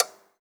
clock_tock_03.wav